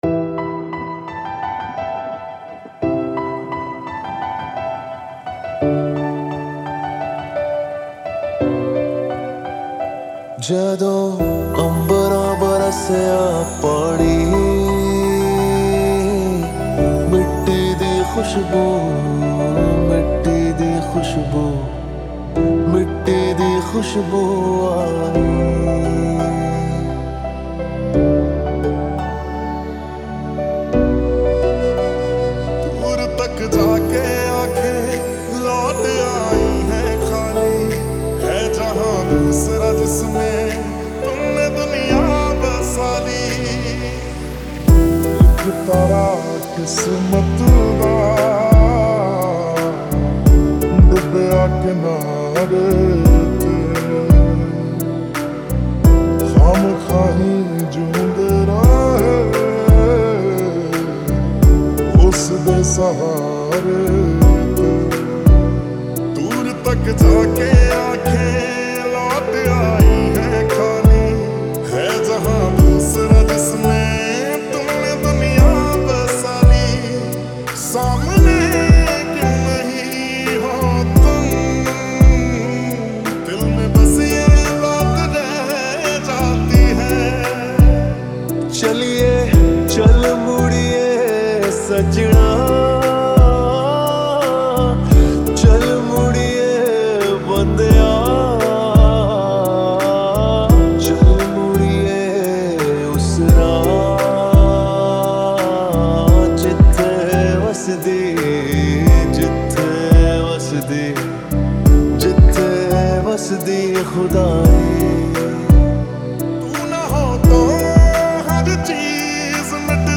Mashup Mixes